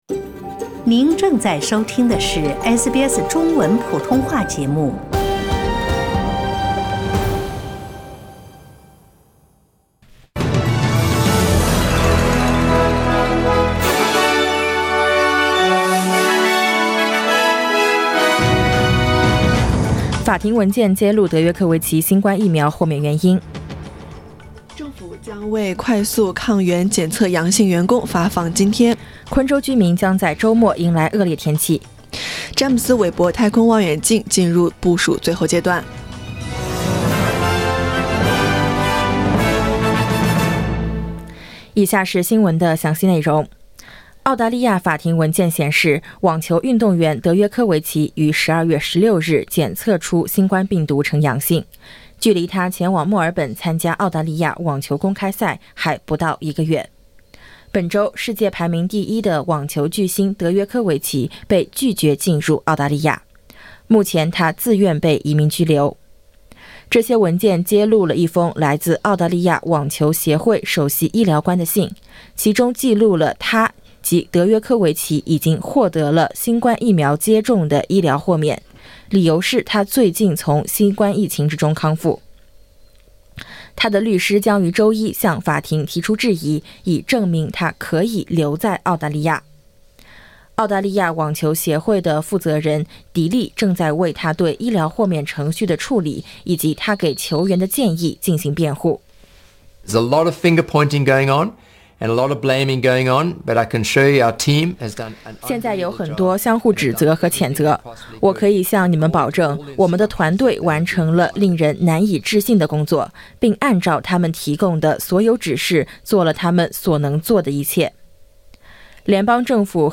SBS早新闻（2022年1月9日）
SBS Mandarin morning news Source: Getty Images